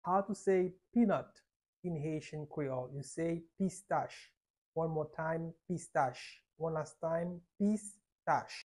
How to say "Peanut" in Haitian Creole - "Pistach" pronunciation by a native Haitian tutor
How-to-say-Peanut-in-Haitian-Creole-Pistach-pronunciation-by-a-native-Haitian-tutor.mp3